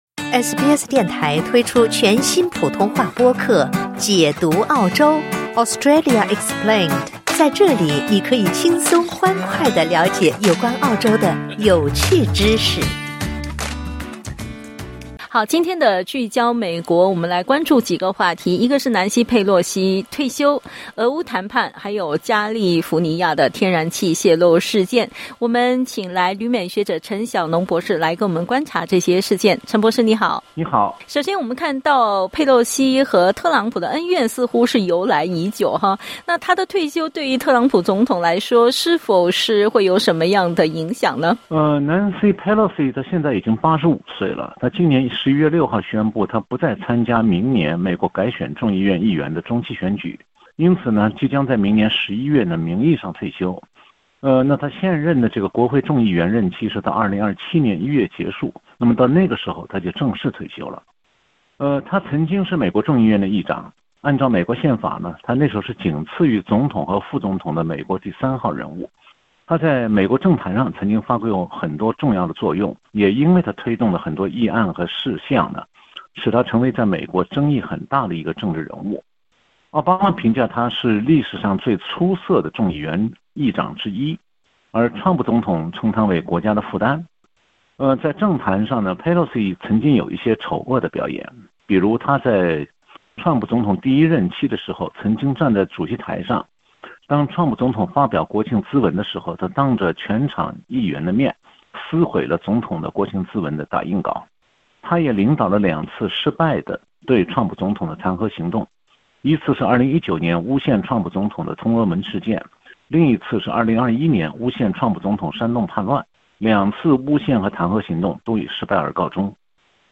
（点击音频收听详细采访） 佩洛西（Nancy Pelosi）和特朗普（Donald Trump）的“恩怨”由来已久，她的退休对特朗普总统来说是否有所影响？